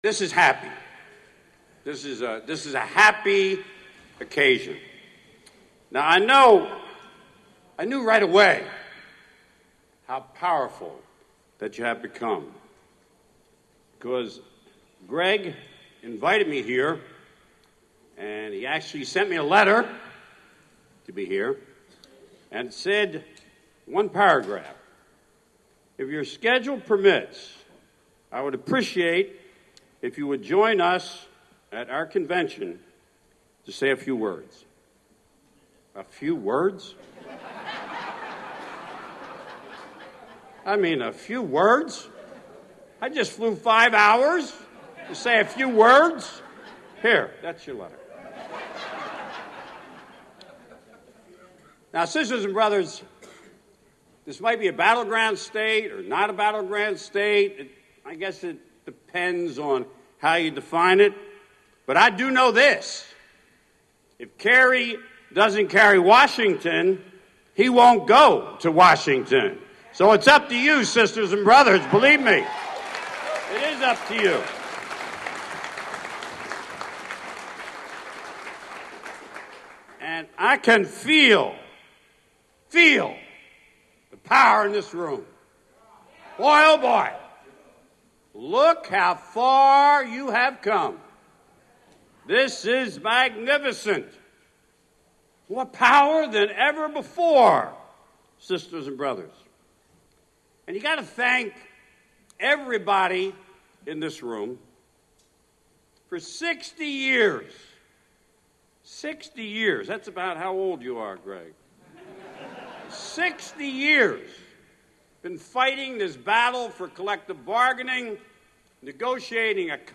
AFSCME International President Gerald McEntee addresses the 41st WFSE/AFSCME State Convention, SeaTac, WA (Oct 9 04)